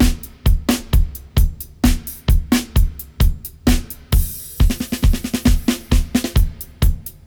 129-FX-02.wav